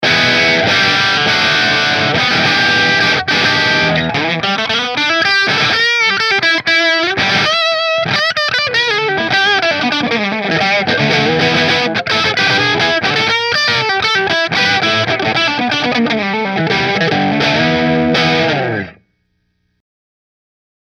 • 2 Custom Wound Humbuckers
New Orleans Guitars Voodoo Custom Natural Bridge Through Marshall